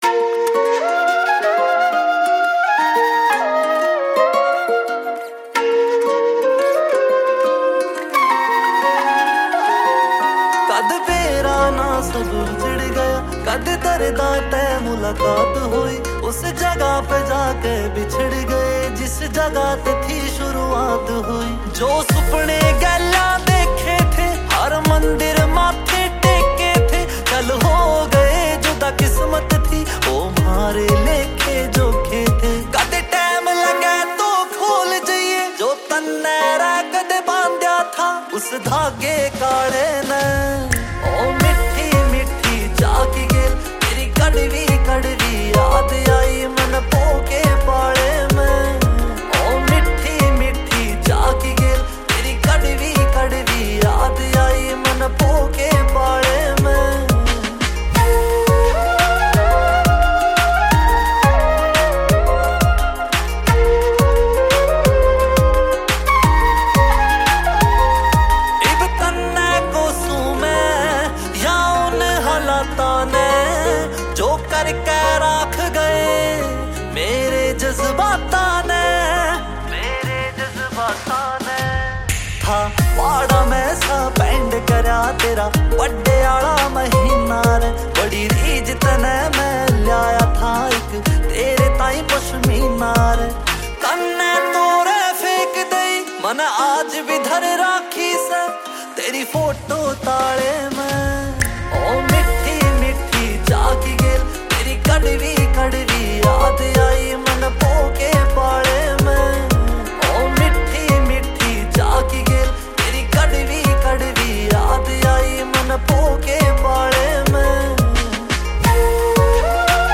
Releted Files Of New Haryanvi Song 2025